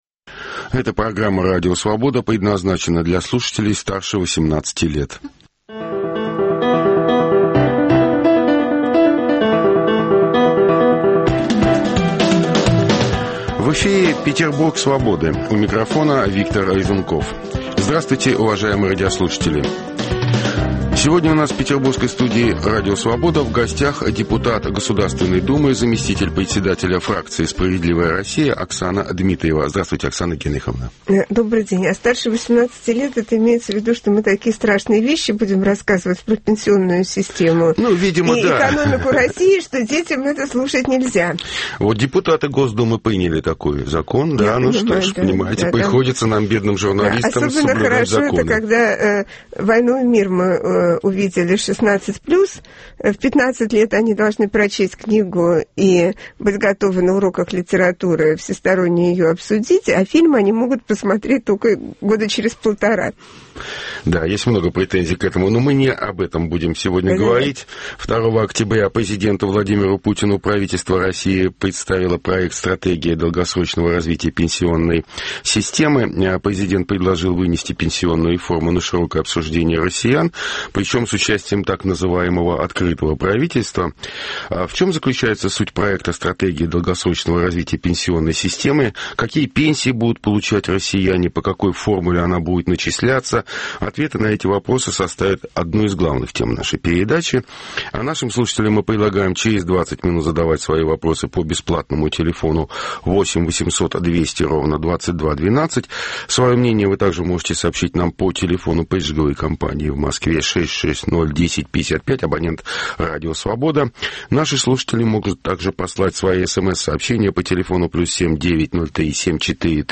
Круглый стол: Петербург Свободы
В чем смысл новой пенсионной реформы, предложенной правительством? Обсуждаем с депутатом Государственной думы Оксаной Дмитриевой (фракция "Справедливая Россия").